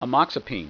Pronunciation
(a MOKS a peen)